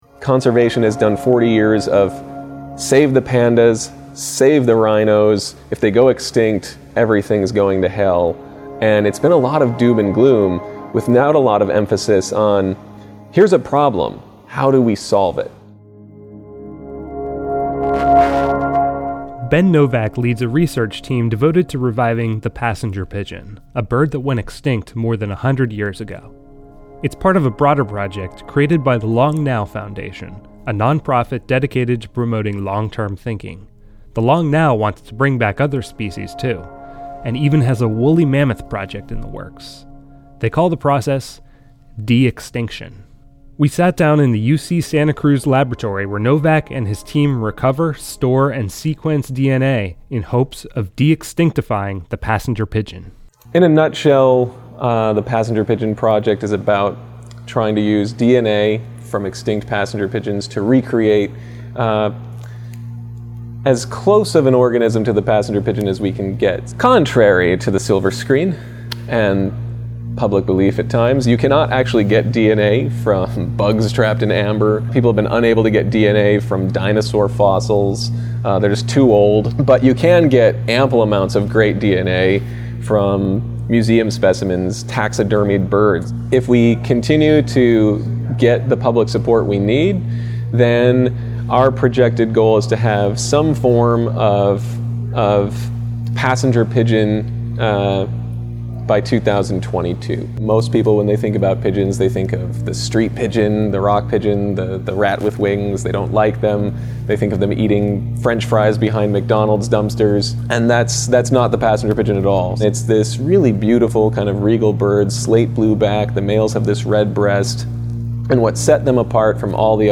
Music by Chris Zabriskie.